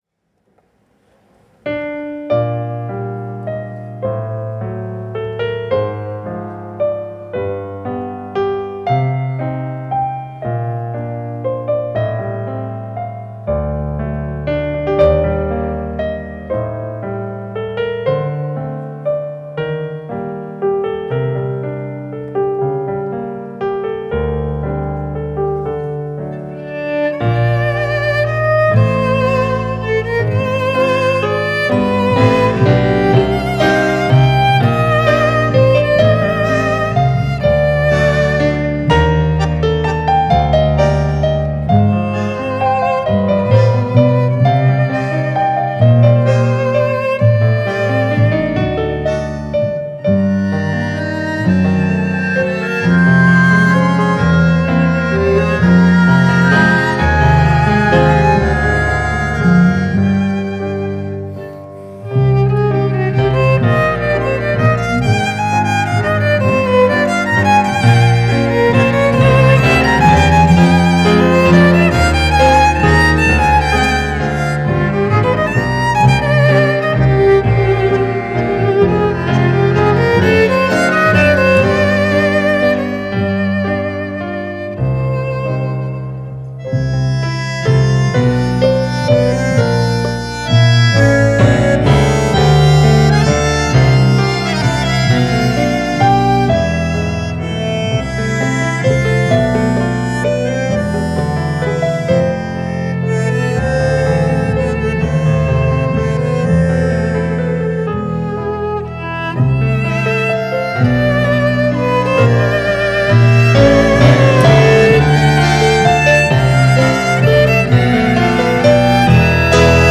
Танго
live